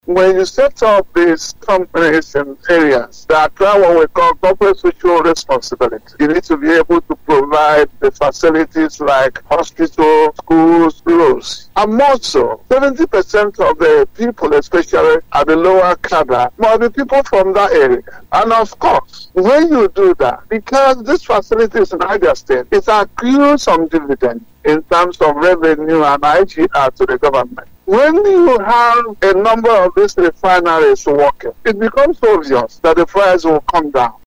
Speaking on Family Love FM’s Open Parliament, monitored by dailytrailnews, the Commissioner for Petroleum and Solid Minerals Prof. Joel Ogbonna maintained that the project is on course and is being pursued with all seriousness and commitment by parties concerned.